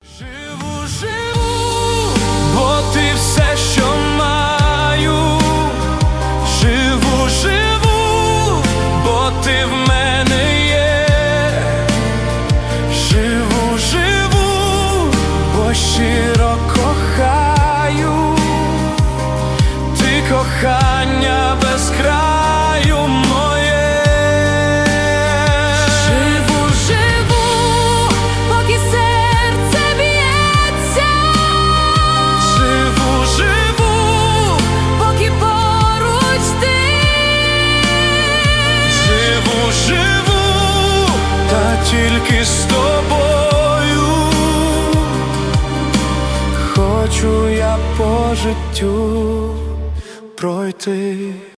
дуэт
поп